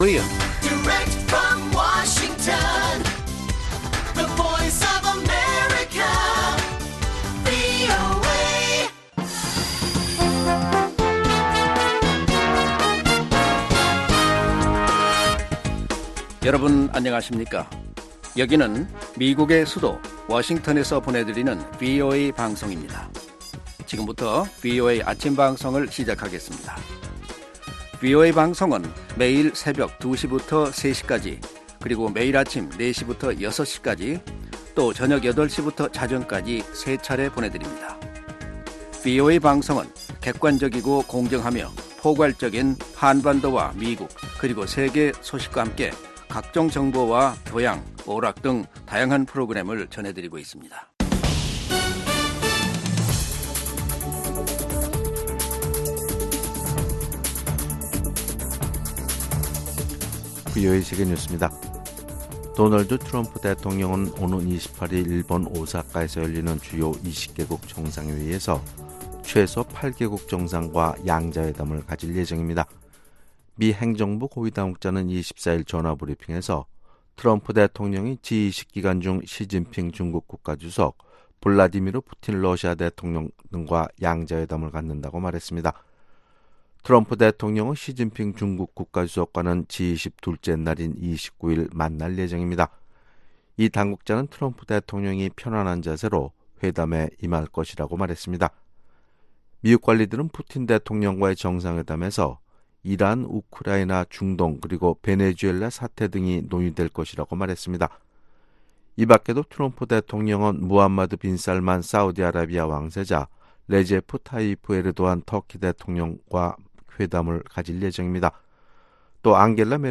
세계 뉴스와 함께 미국의 모든 것을 소개하는 '생방송 여기는 워싱턴입니다', 2019년 6월 26일 아침 방송입니다.